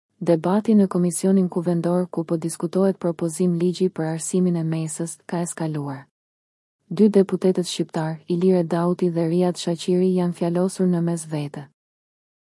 AUDIO LAJM duke përdorur intelegjencën artificiale